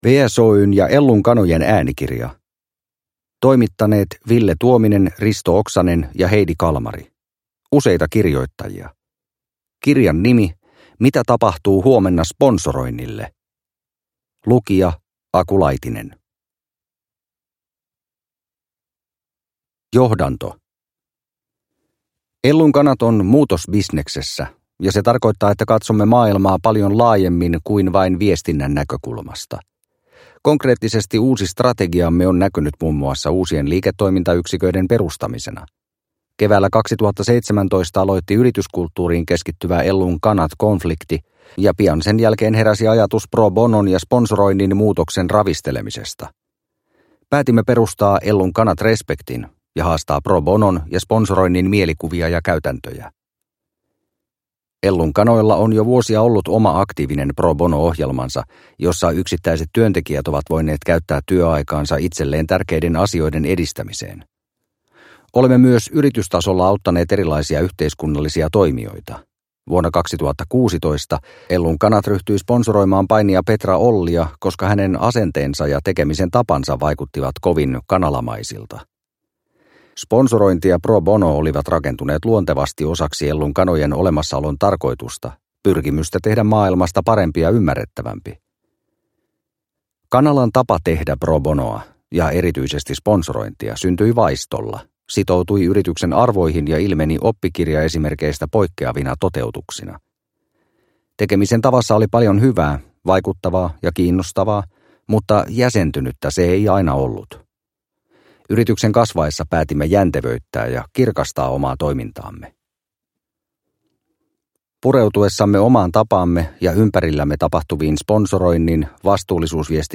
Mitä tapahtuu huomenna sponsoroinnille? – Ljudbok